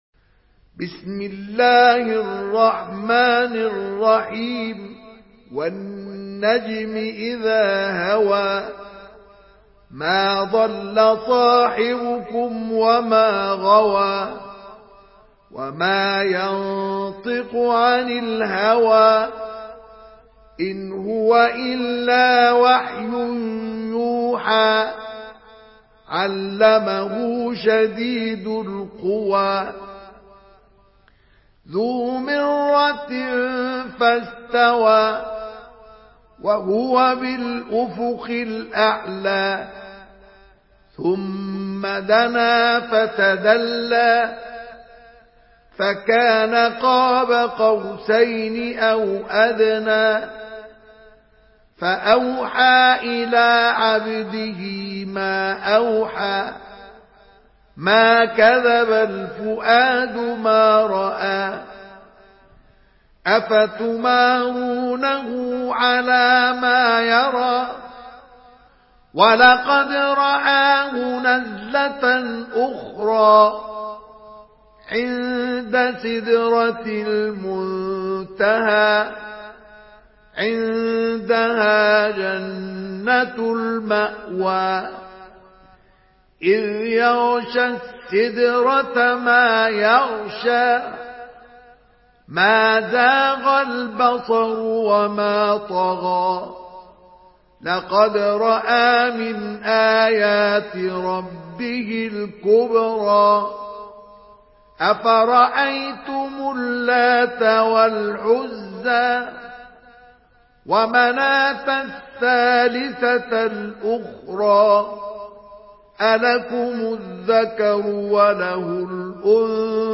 Surah An-Najm MP3 by Mustafa Ismail in Hafs An Asim narration.
Murattal